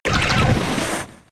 Cri de Triopikeur K.O. dans Pokémon X et Y.